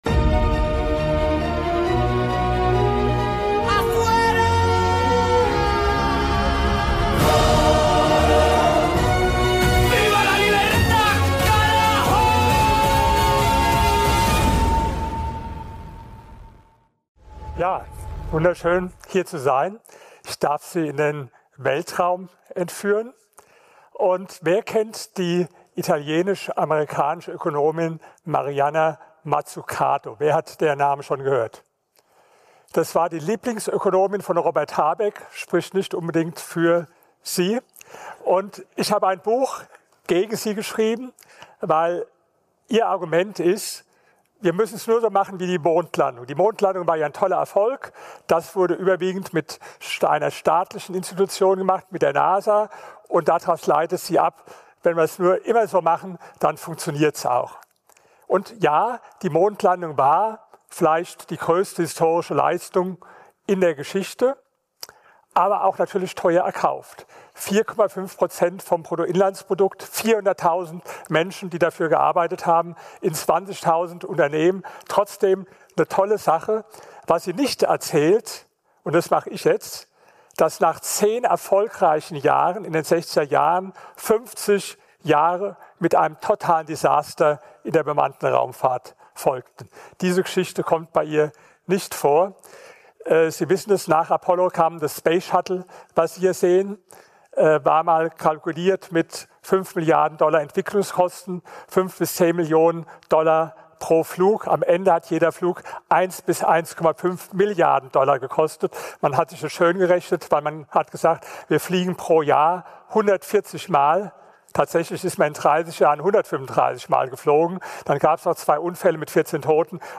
Episode #260 - Vortrag von Dr. Dr. Rainer Zitelmann - Milei Institut